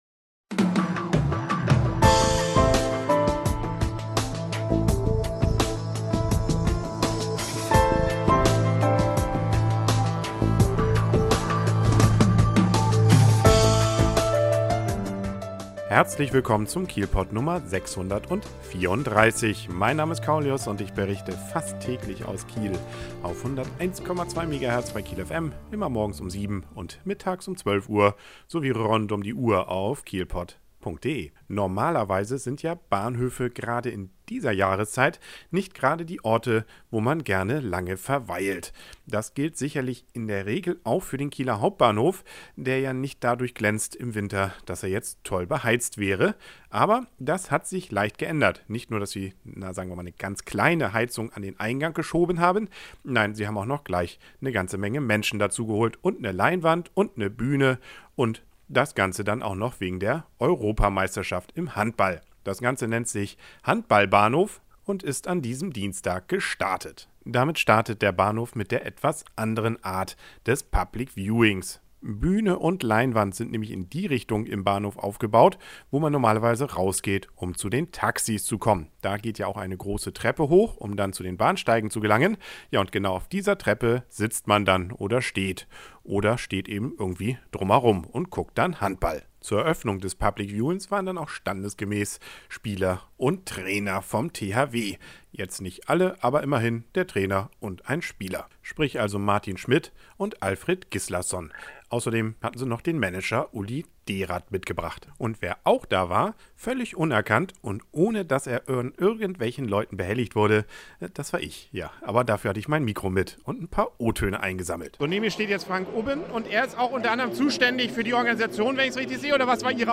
Kieler Hauptbahnhof